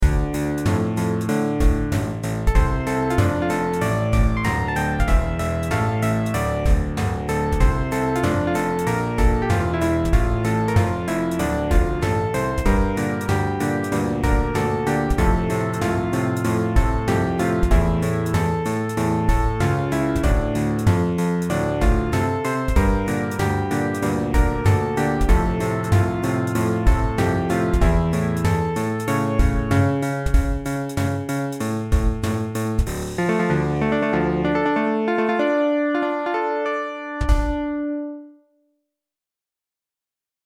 Right now. I only have a piano.